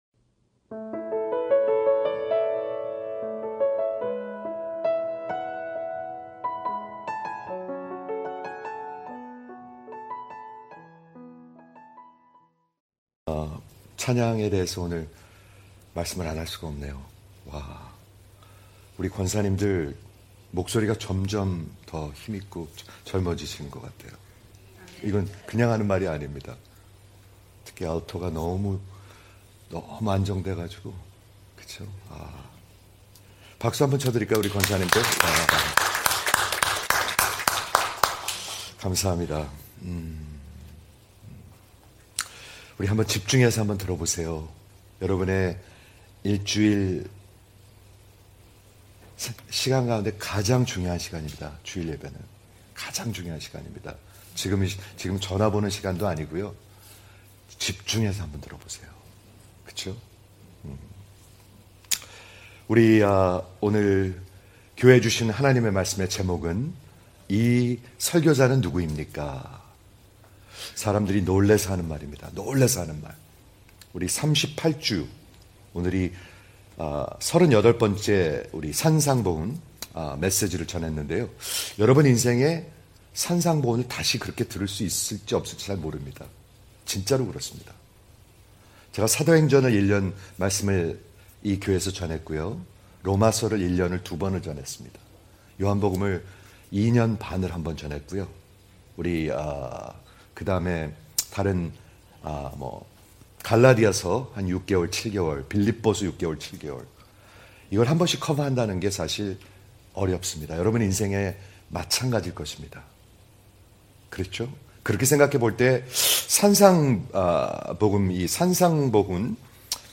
Gospel of Matthew (49) 이 설교자 누구 입니까 (Sermon on the Mount 38 – Epilogue) - New Heart Mission Church